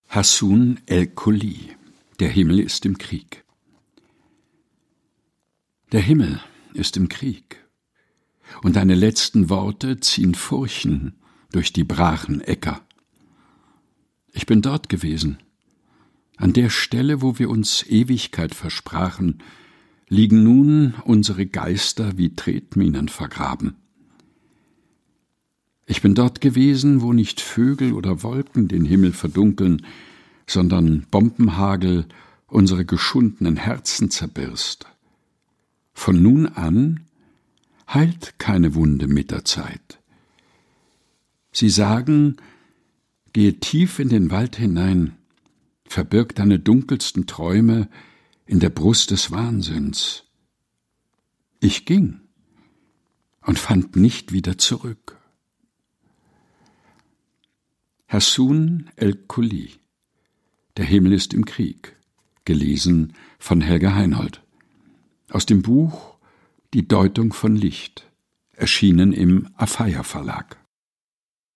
Ohrenweide ist der tägliche Podcast mit Geschichten, Gebeten und Gedichten zum Mutmachen und Nachdenken - ausgesucht und im heimischen Studio vorgelesen